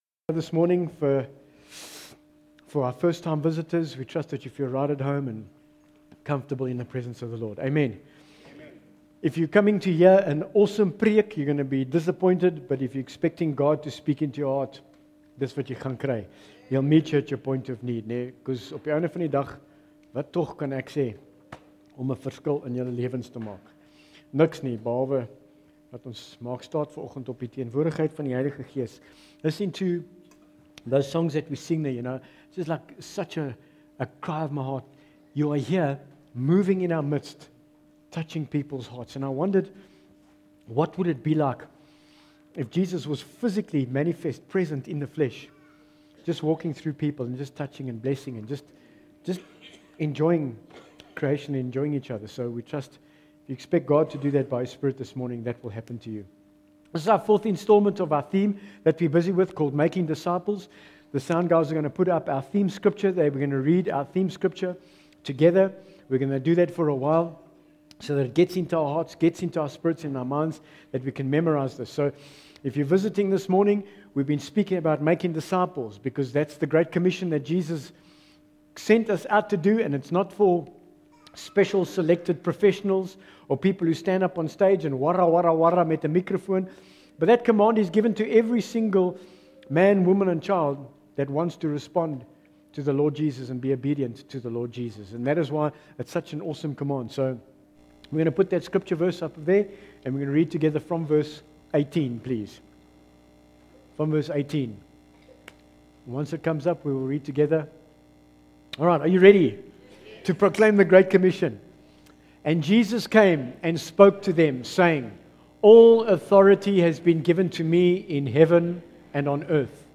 Bible Text: MATTHEW 28:18-20 | Preacher